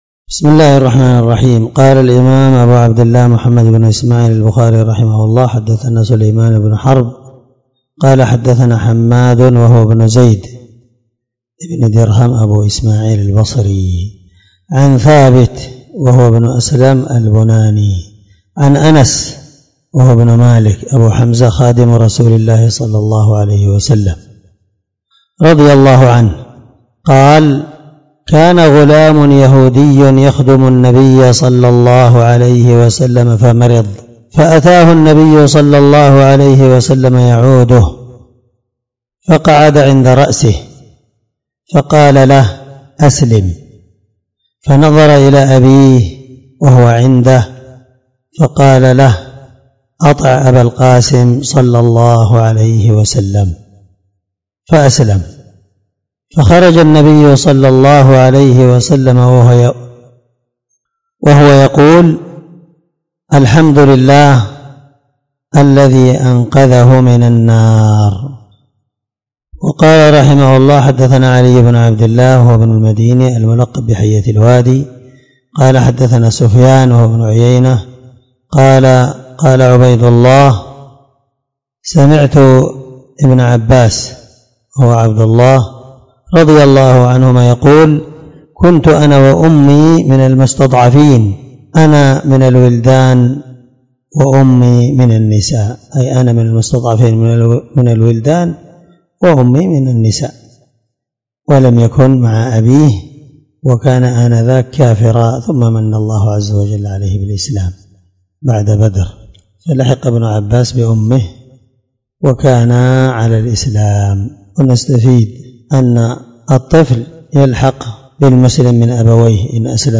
782الدرس 55من شرح كتاب الجنائز حديث رقم(1356-1359 )من صحيح البخاري